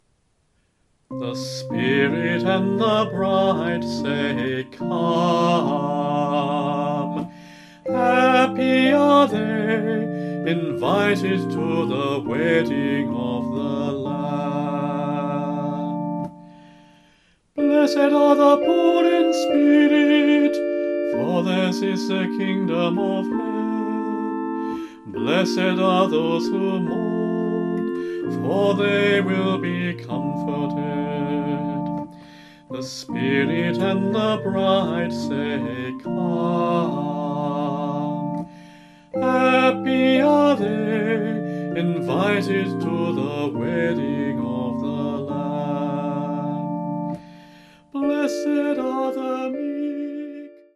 A demo recording will be found here.